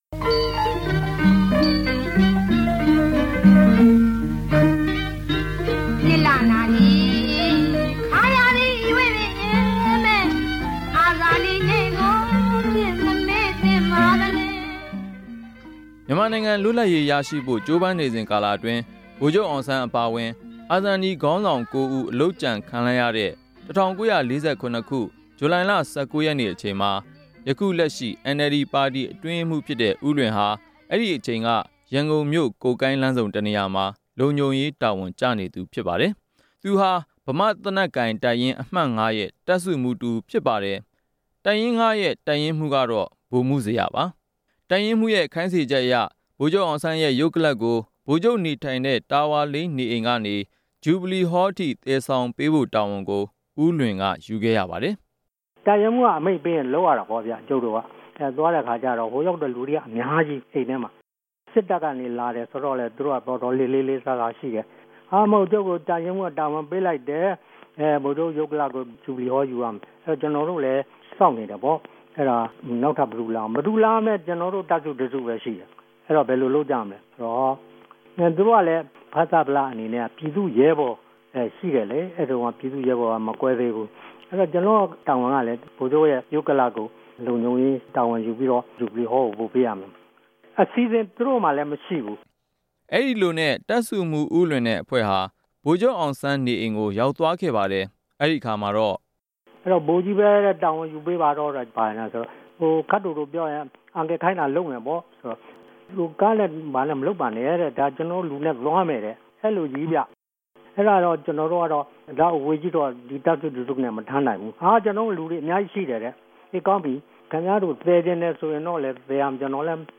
တခဵိန်က ိံိုင်ငံတော် ဒု-ဝန်ဋ္ဌကီးခဵြပ်ဟောင်းလည်းူဖစ်၊ အဆင့်ူမင့် စစ်အရာရြိဟောင်းတဦးလည်းူဖစ်တဲ့ ဦးလြင်က ူပည်သူတေဟြာ ဗိုလ်ခဵြပ်အောင်ဆန်းကို ခဵစ်ူမတိံိုးတဲ့စိတ်ေုကာင့် ဗိုလ်ခဵြပ်ရဲႛရုပ်ကလာပ်ကို အလုအယက်သယ်ယူလိုေုကာင်း သူႛအတြေႛအုကြံတေကြို အာဇာနည်နေႛအမြတ်တရအူဖစ် RFA ကို ေူပာဆိုခဲ့ပၝတယ်။